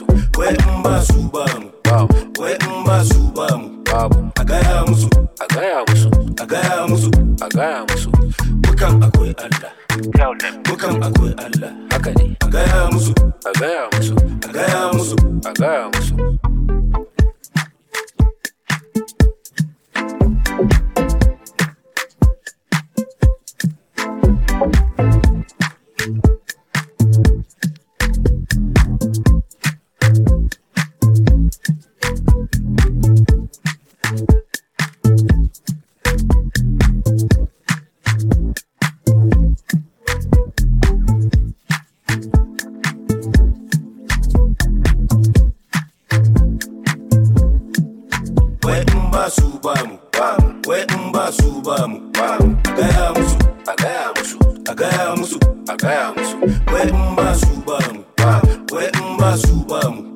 Open Verse beat